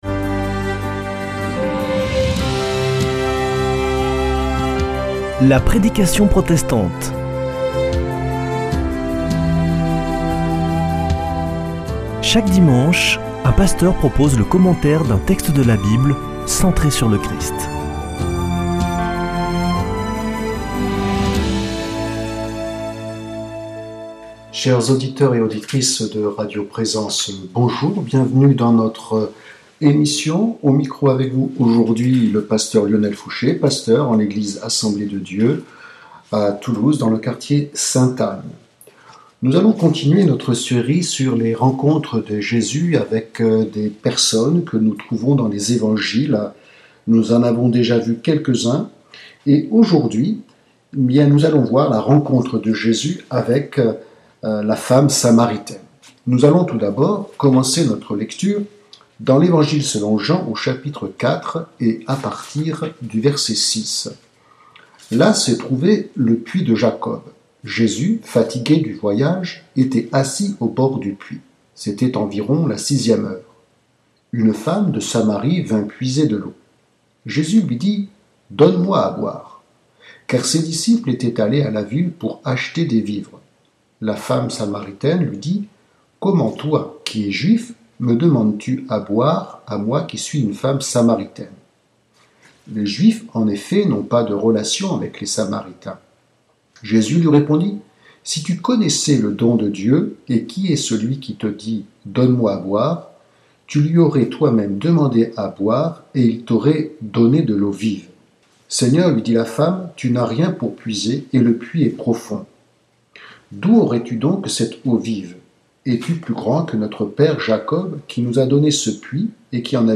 La prédication protestante